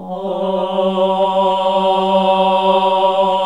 AAH G1 -R.wav